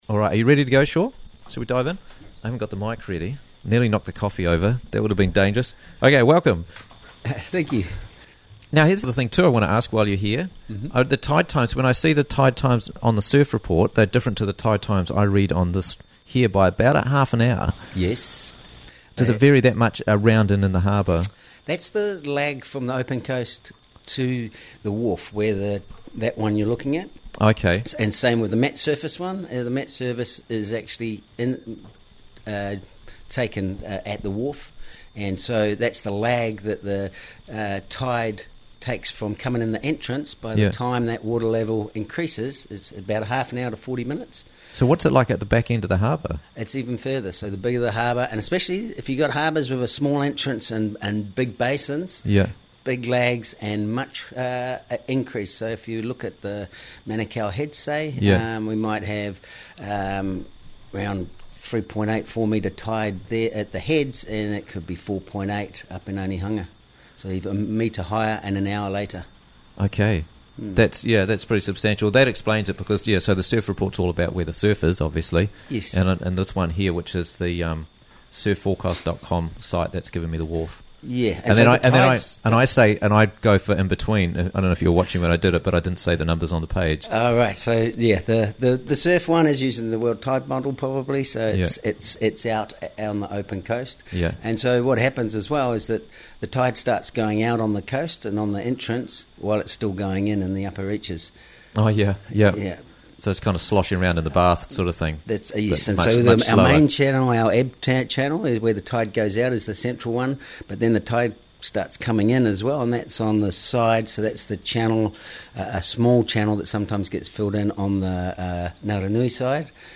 - Interviews from the Raglan Morning Show